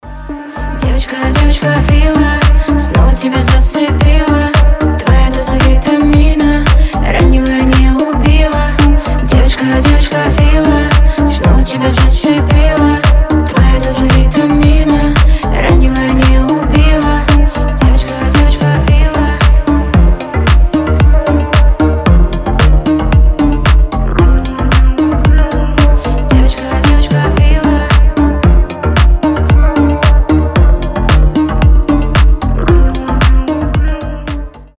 • Качество: 320, Stereo
поп
deep house